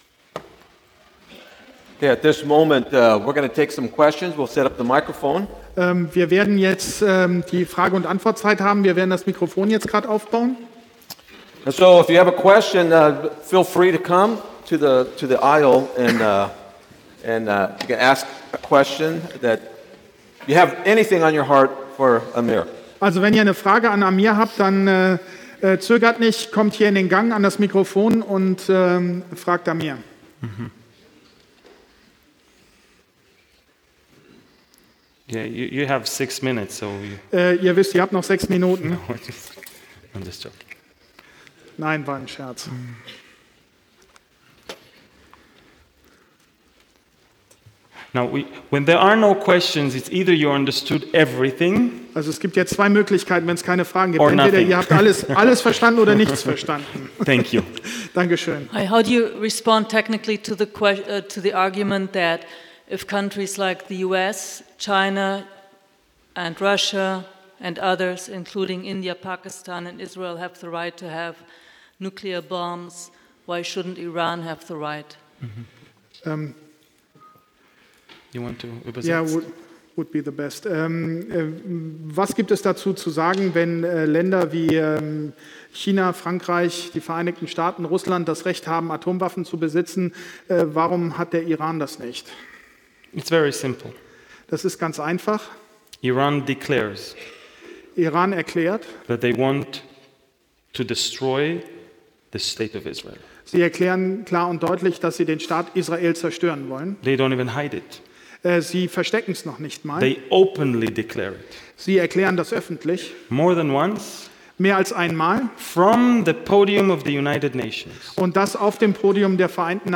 CCH_PK2010_Ses2_QA_at.mp3